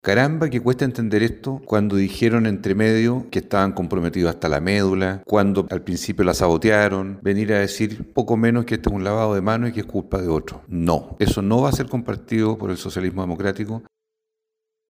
El senador Juan Luis Castro (PS) criticó a la dirección del PC por intentar desvincularse de su rol en la campaña de Jeannette Jara, pese a que, según afirmó, habría existido un “sabotaje” desde antes del inicio formal del proceso.